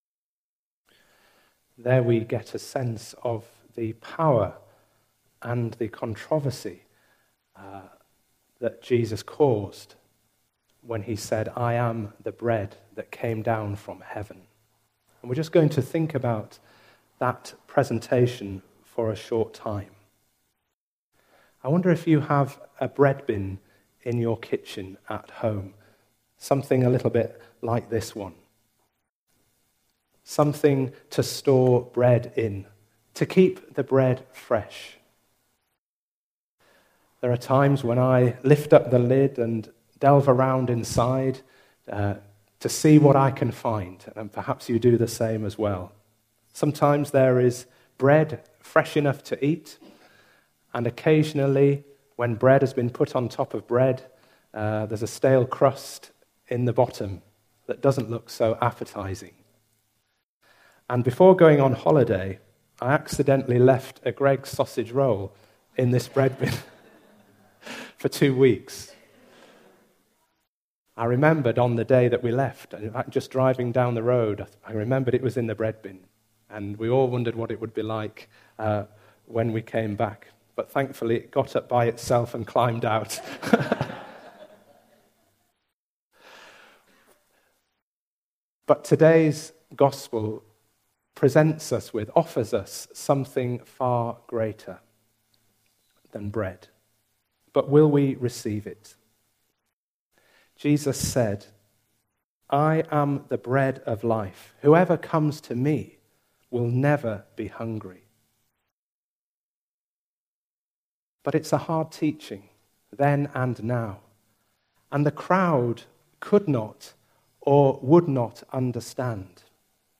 A service of morning worship in a contemporary style.
Service Type: Sunday Morning